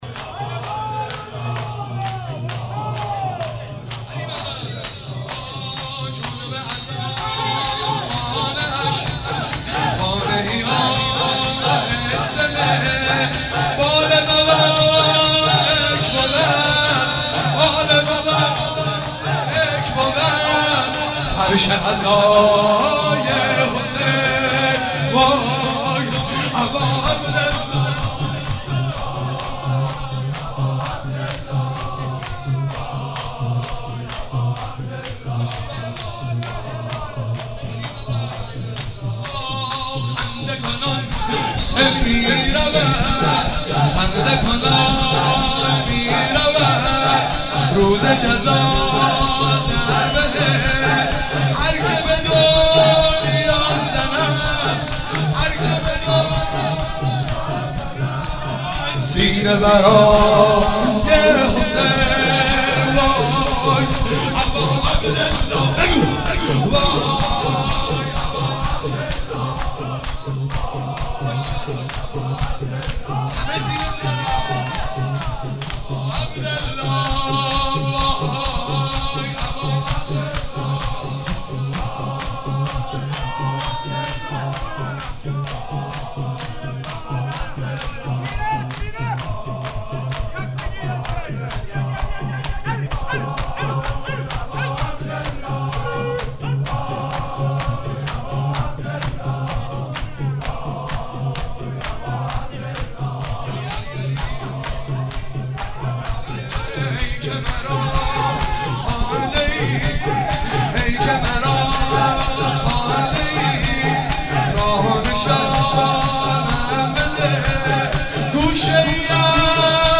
چهاراه شهید شیرودی حسینیه حضرت زینب (سلام الله علیها)
مدح و روضه حضرت رقیه(س)